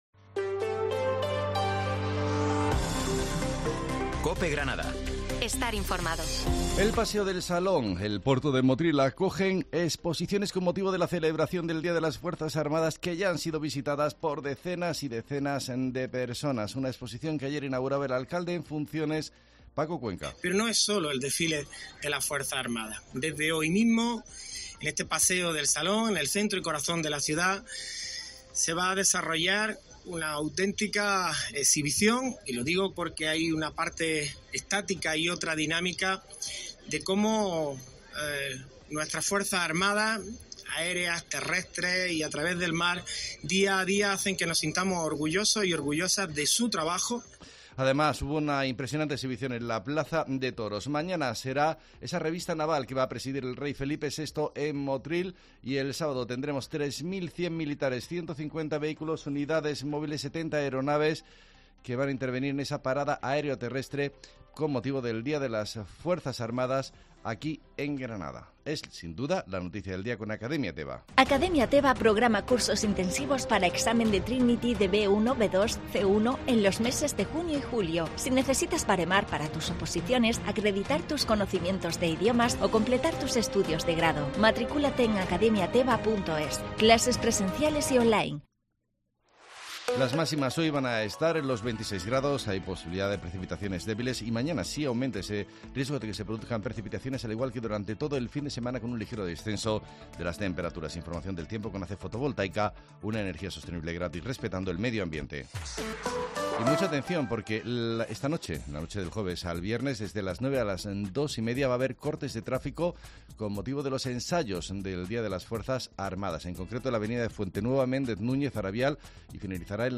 Herrera en COPE Granada, Informativo del 1 de junio